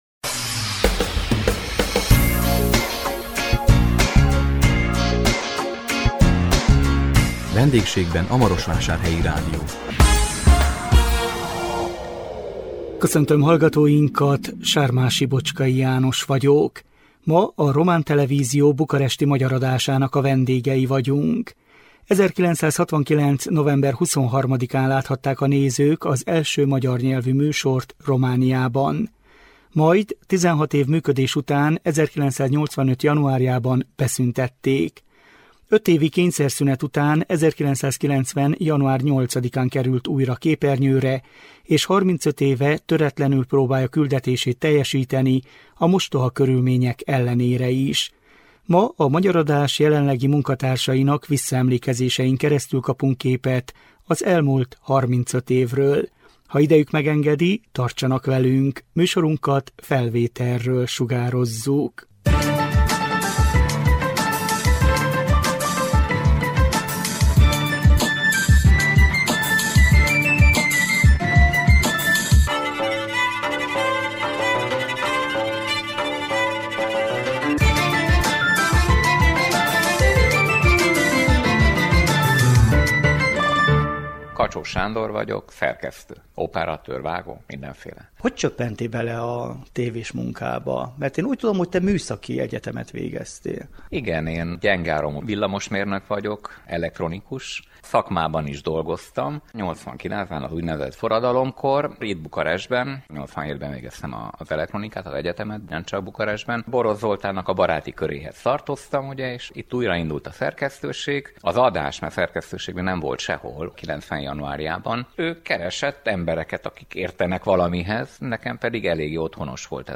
A 2025 május 8-án közvetített VENDÉGSÉGBEN A MAROSVÁSÁRHELYI RÁDIÓ című műsorunkkal a Román Televízió bukaresti Magyar Adásának a vendégei voltunk. 1969 november 23-án láthatták a nézők az első magyar nyelvű műsort Romániában. Majd 16 év működés után 1985 januárjában beszüntették. 5 évi kényszerszünet után 1990 január 8-án került újra képernyőre és 35 éve töretlenül próbálja küldetését teljesíteni a mostoha körülmények ellenére is. A Magyar Adás jelenlegi munkatársainak visszaemlékezésein keresztül kapunk képet az elmúlt 35 évről.